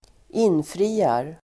Uttal: [²'in:fri:ar]